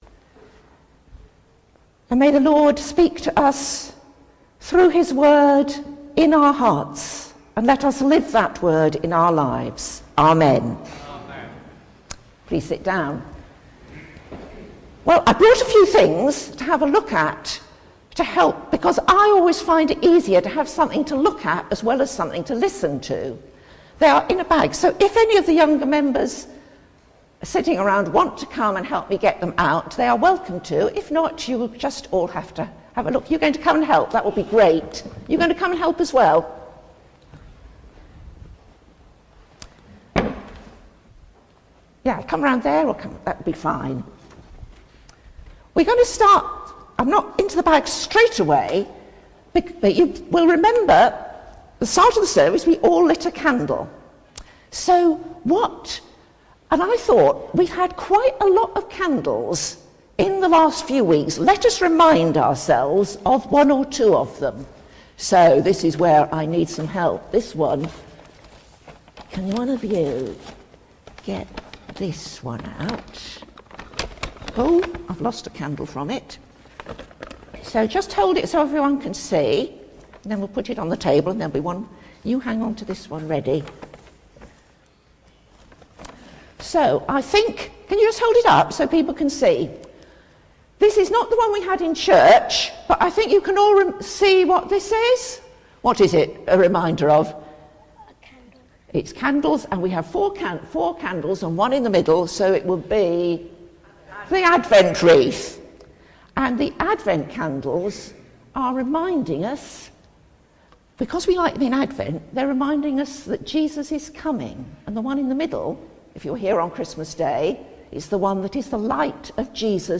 Sermon
Feb1_2026_Sermon.mp3